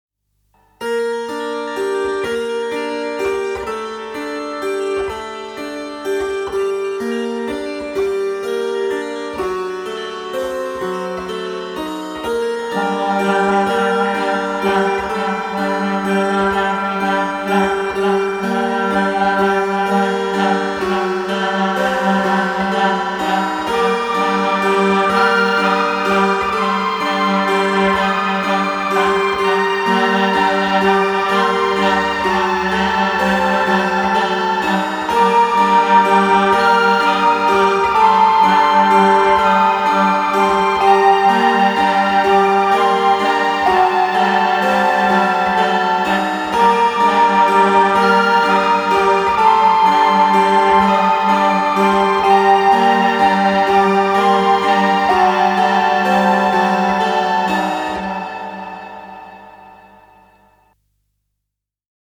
indie electronic band